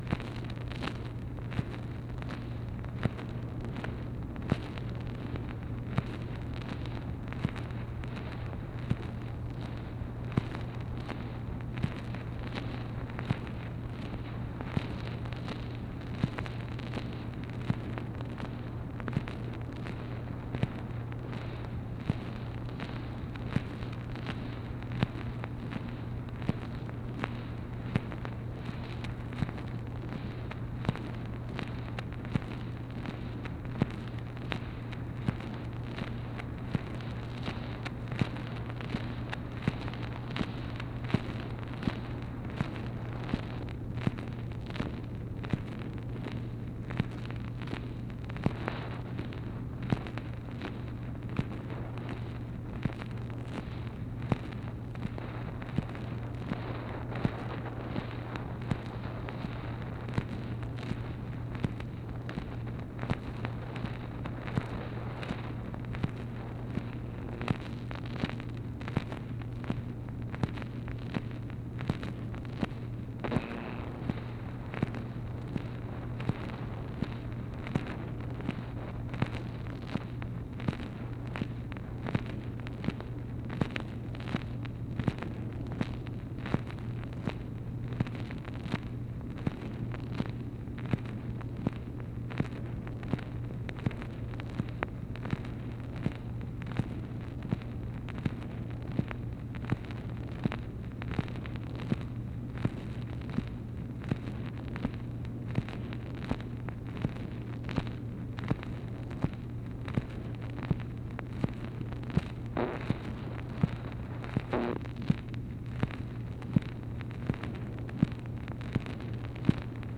MACHINE NOISE, April 9, 1964
Secret White House Tapes | Lyndon B. Johnson Presidency